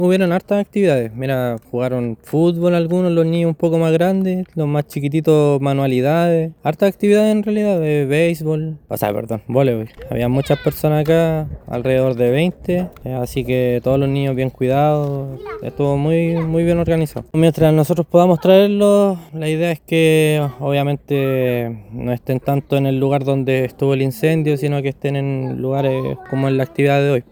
Uno de los apoderados, se mostró agradecido con la iniciativa.